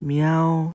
meow.mp3